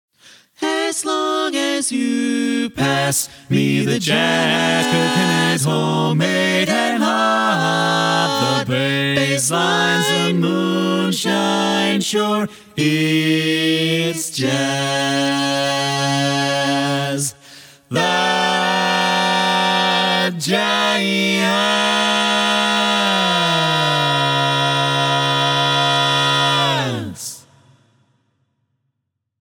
Key written in: D♭ Major
How many parts: 4
Type: Barbershop
All Parts mix:
Learning tracks sung by